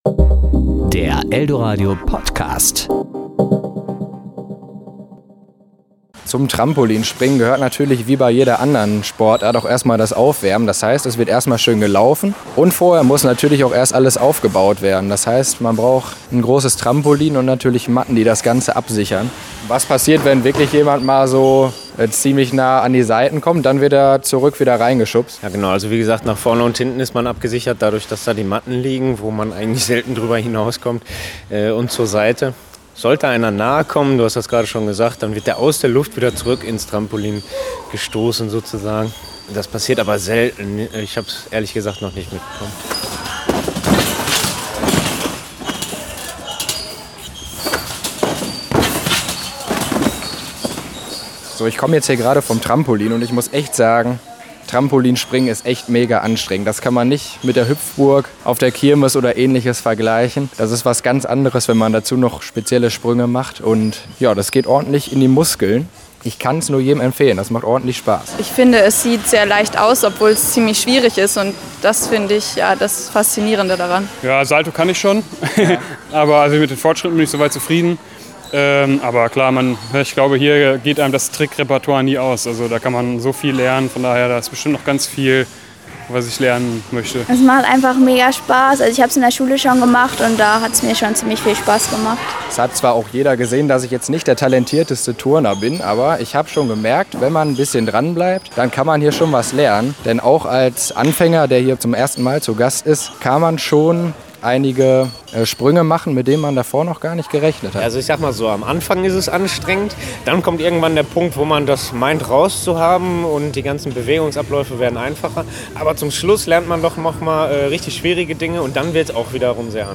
Herumliegende Socken, dicke Matten und ein riesiges Trampolin in der Mitte - schon auf dem ersten Anblick erkennt man, was die Sportler in der Unisporthalle machen.